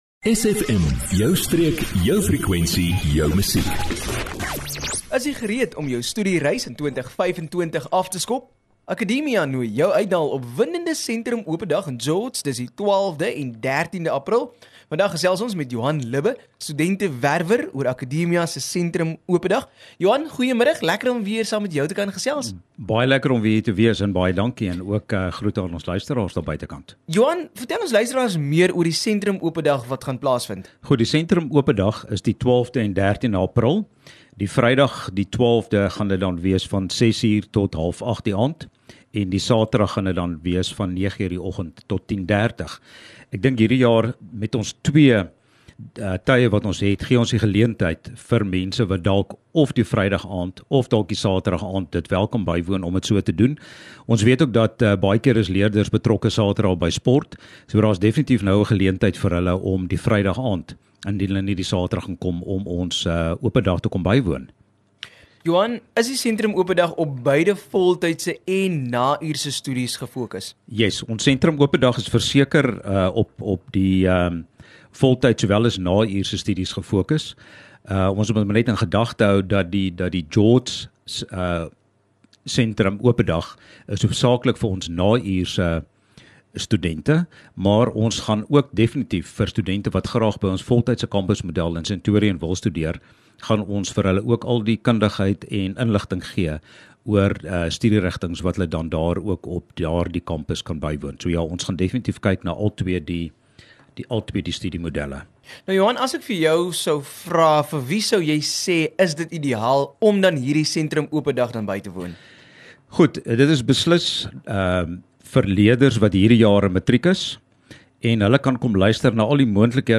SFM Allegaartjie van onderhoude